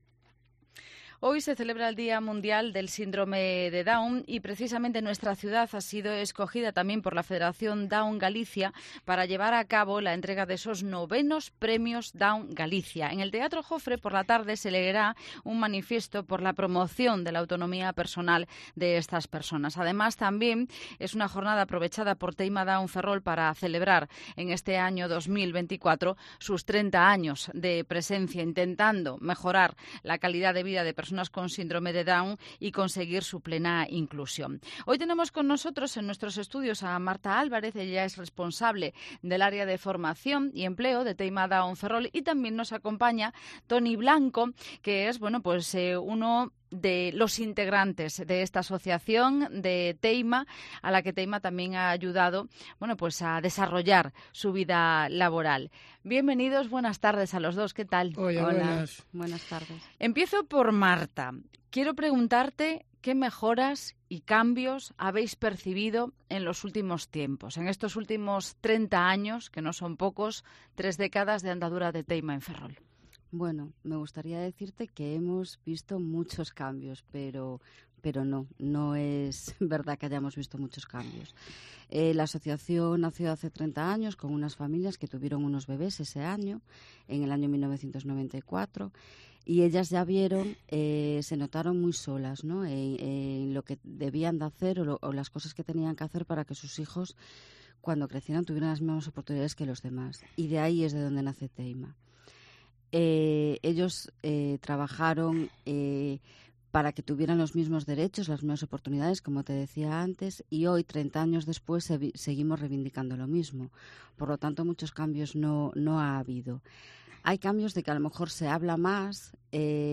en los estudios de COPE Ferrol